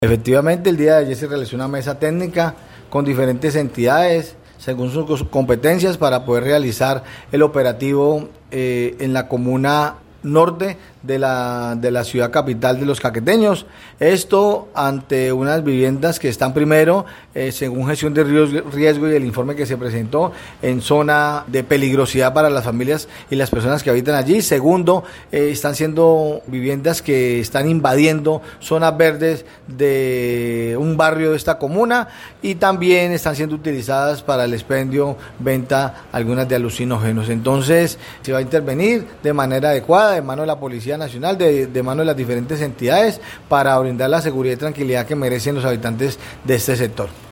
Carlos Mora, secretario de gobierno municipal, explicó que, las viviendas, se ubican en un barrio de la comuna norte, donde, además, se encuentran invadiendo una zona verde, razón por la cual fueron objeto de sendos procesos administrativos para su derribamiento.